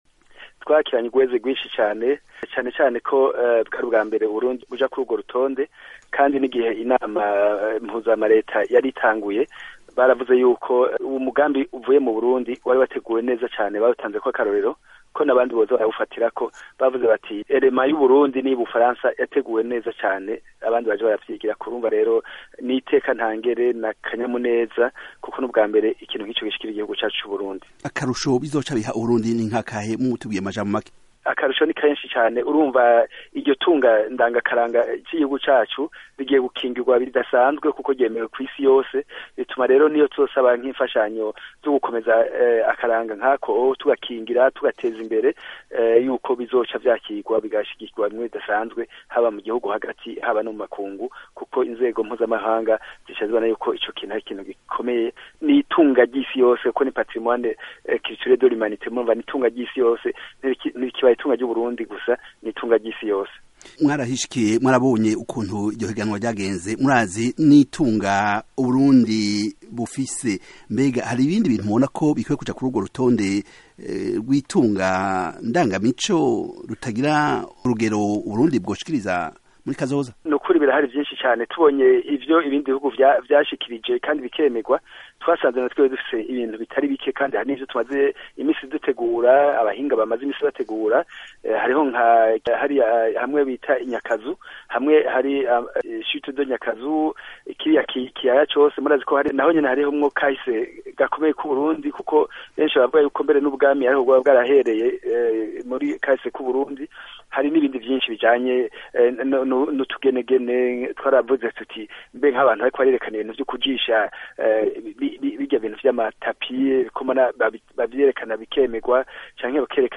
Reka dutege amatwi umushikiranganji w’Uburundi ajejwe Urwaruka Inkino hamwe n’Imico Kama, Adolphe Rukenkanya, yari yishikiye I Paris mu gihugu c’Ubufaransa ku cicaro c’ivyo biro, arinaho kandi ibirori vyo gushikiriza ibindi bihugu bifise ibintu vyagiye kur’urwo rutonde vyabereye, mu kiganiro yahaye mugenzi wacu